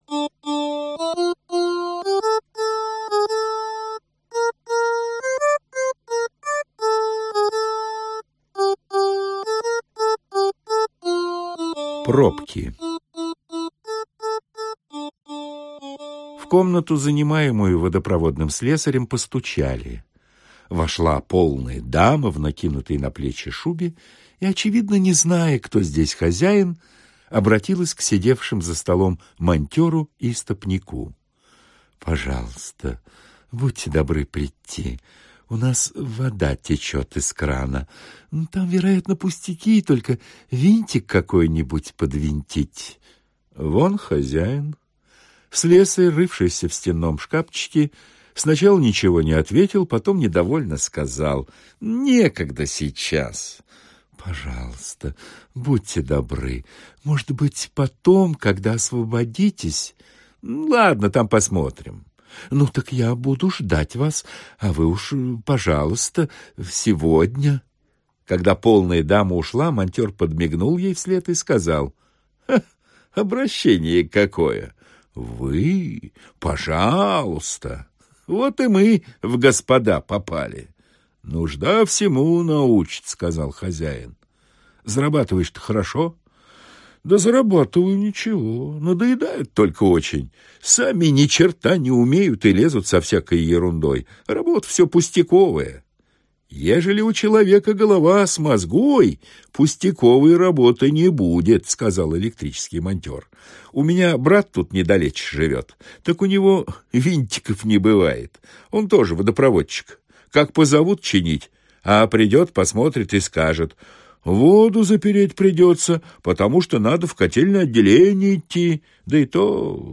Аудиокнига Арабская сказка. Сатирические рассказы | Библиотека аудиокниг